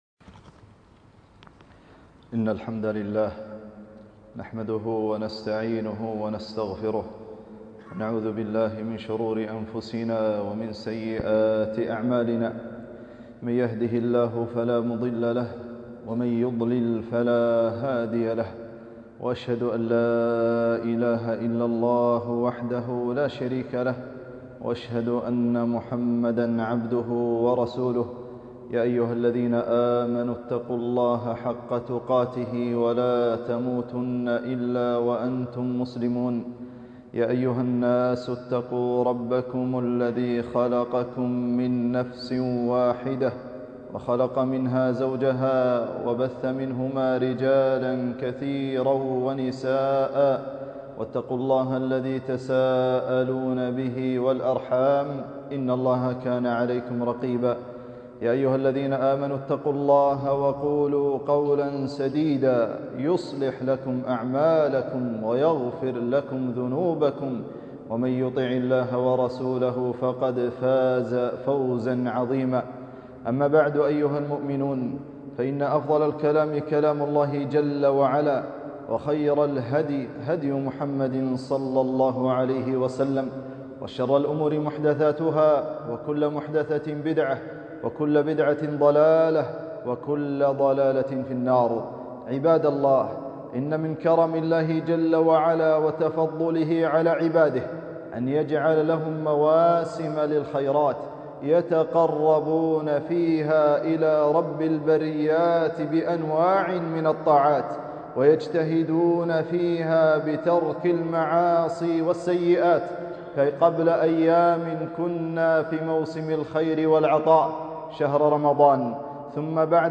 خطبة - فضل عشر ذي الحجة 1443